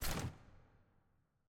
sfx_ui_research_panel_close.ogg